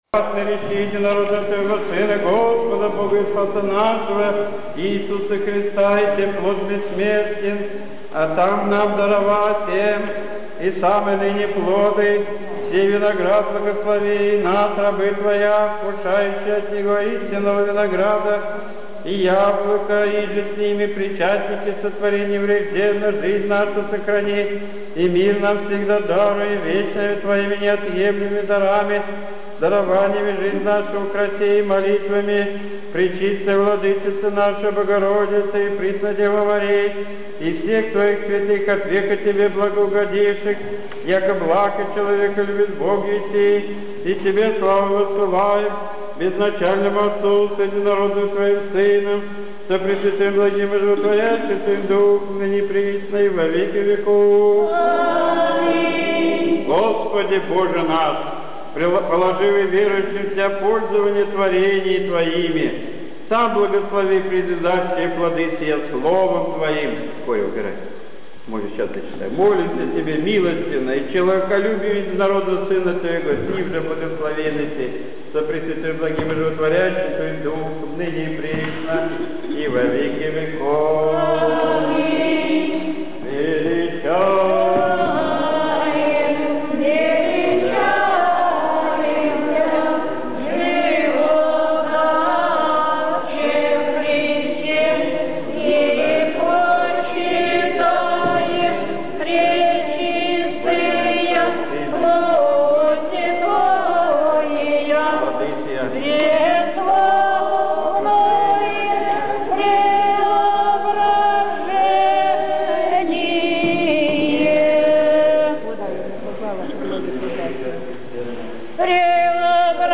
Были освящены яблоки нового урожая.
Яблочный  Спас